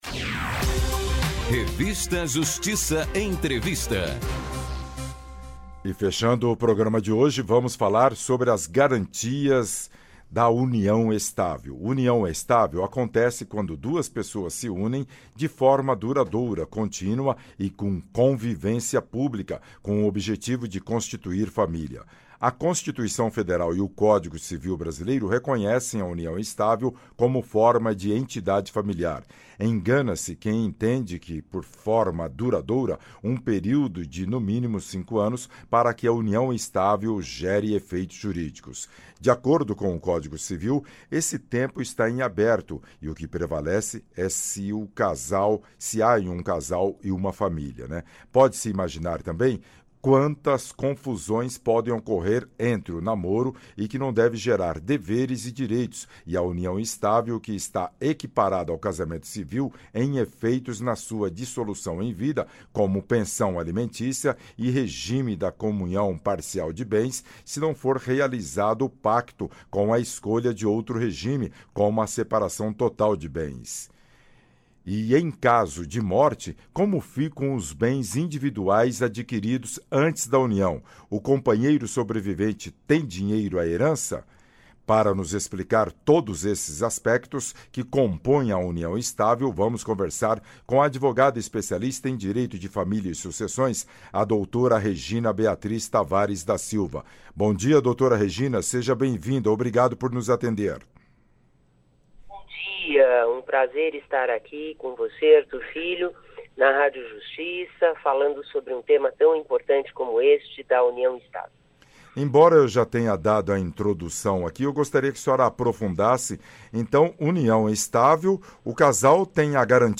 Entrevistas dos membros da Academia Paulista de Letras Jurídicas